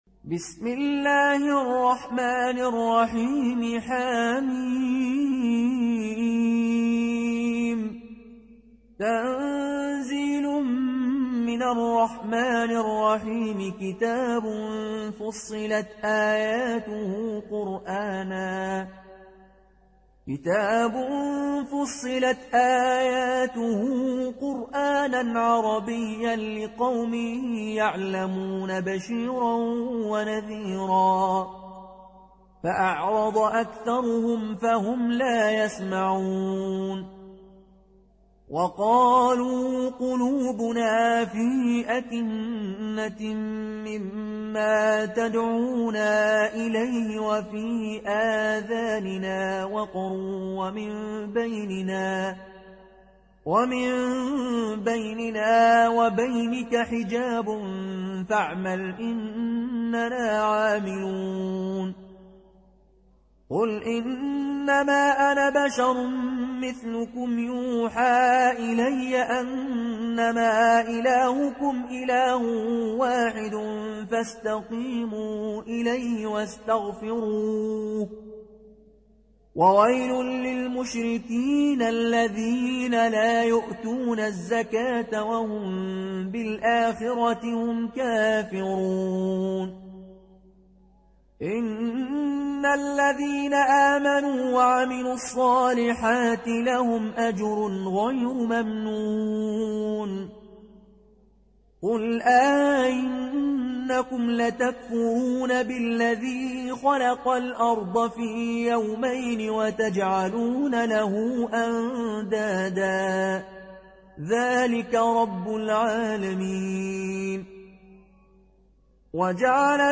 Riwayat Qaloon an Nafi